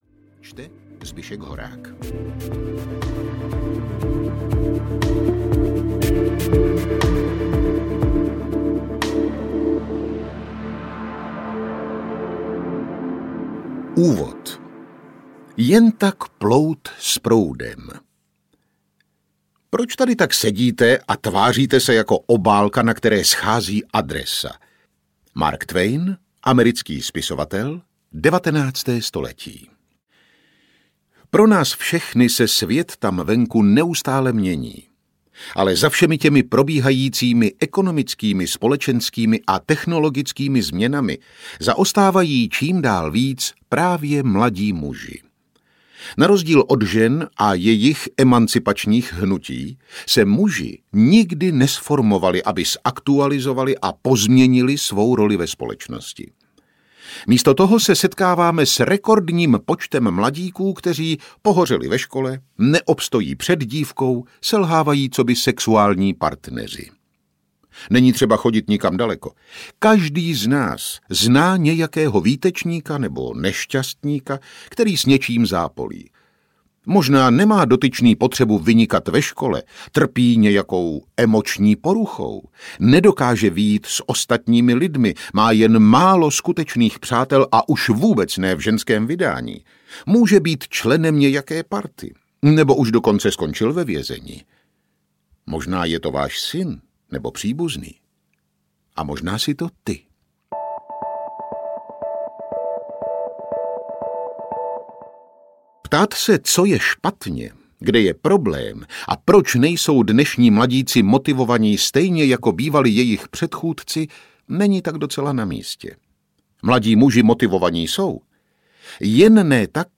Odpojený muž audiokniha
Ukázka z knihy
odpojeny-muz-audiokniha